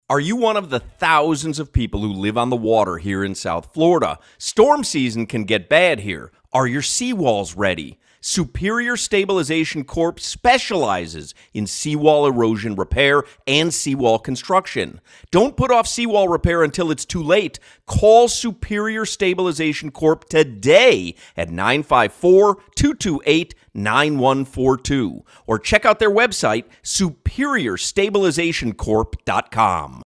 Listen to our new radio commercial below!